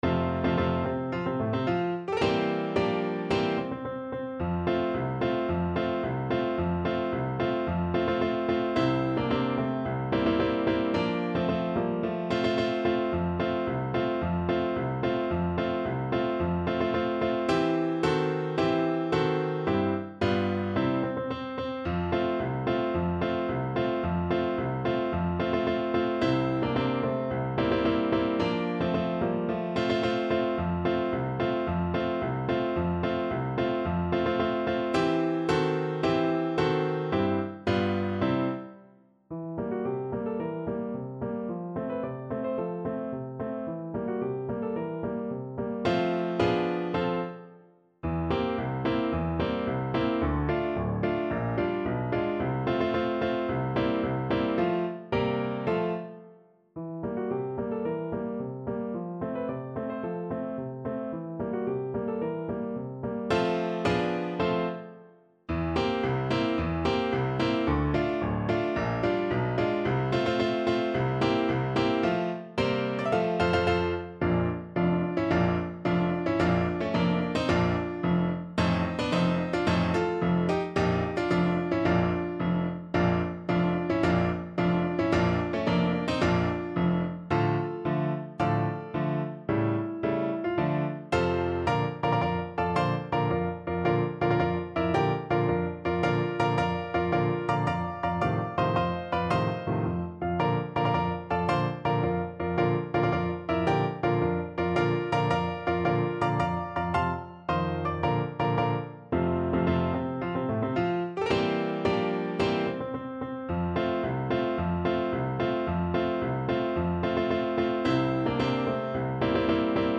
2/2 (View more 2/2 Music)
F major (Sounding Pitch) (View more F major Music for Trombone )
March =c.110